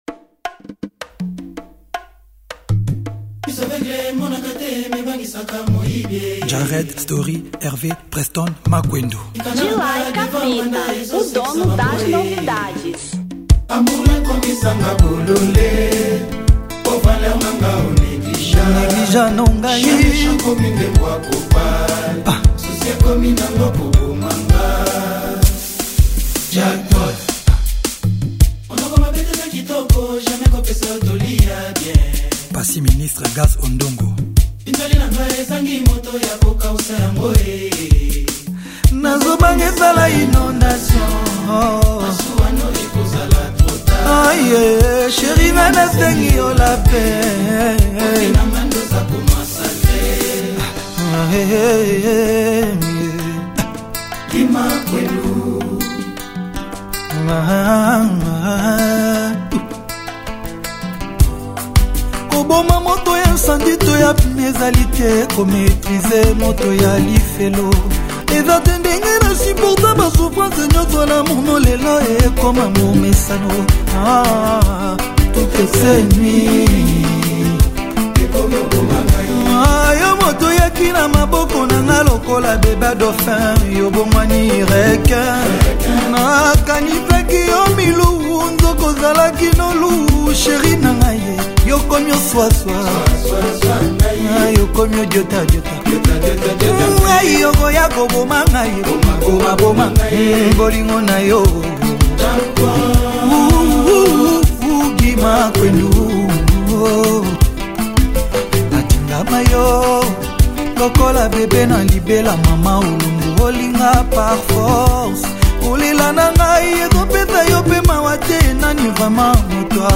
Rumba 2013